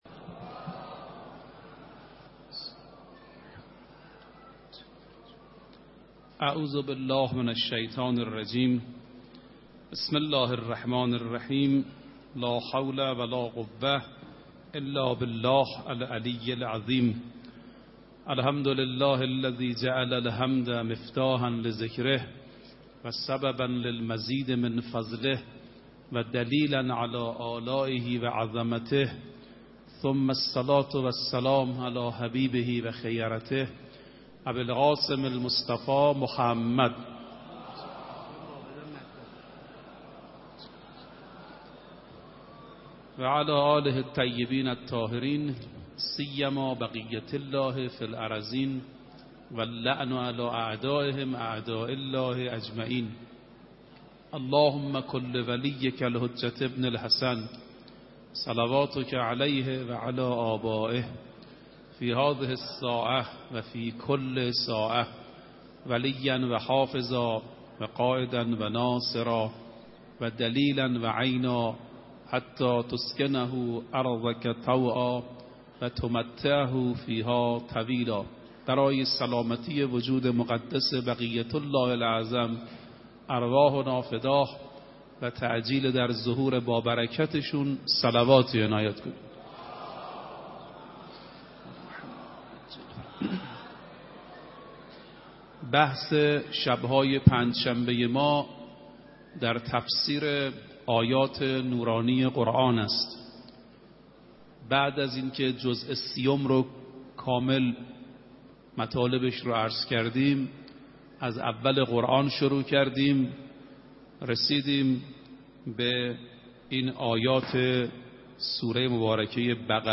22 آذر 96 - حرم حضرت معصومه - قساوت قلب و برخی راههای درمان
سخنرانی